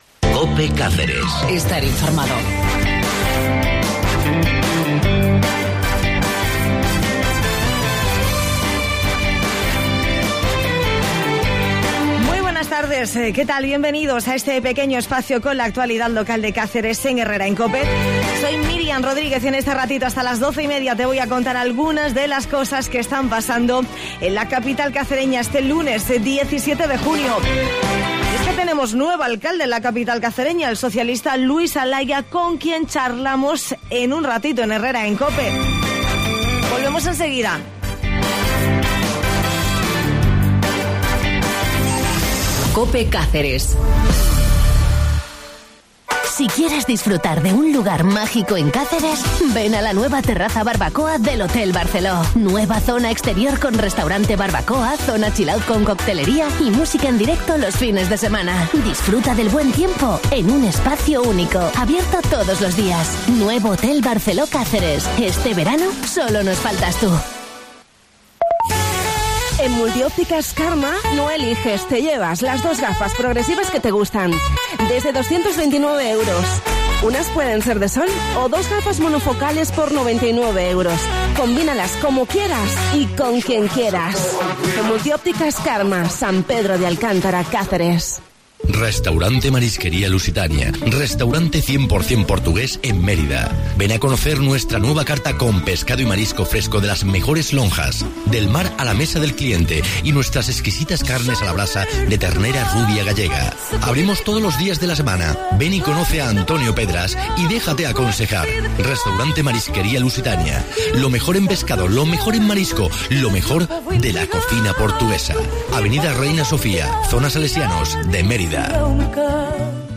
En Herrera en Cope hablamos con el nuevo alcalde de Cáceres, Luis Salaya